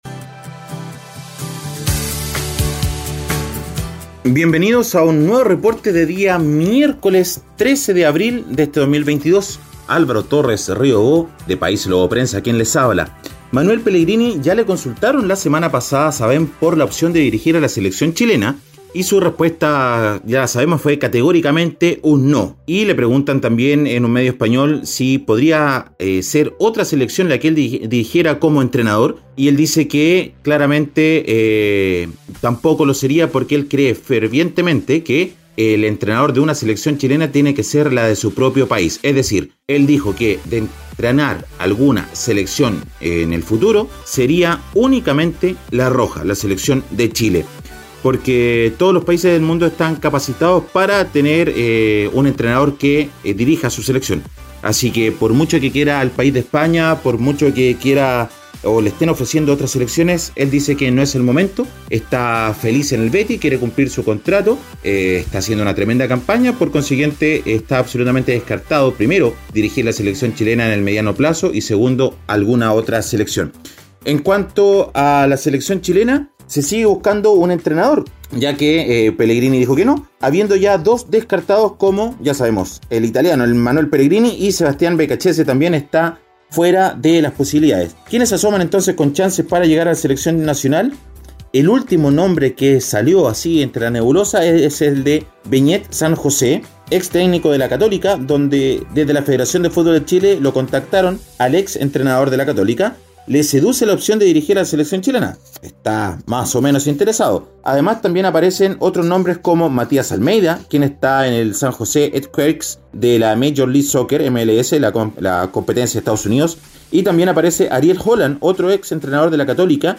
Reporte Deportivo ▶ Podcast 13 de abril de 2022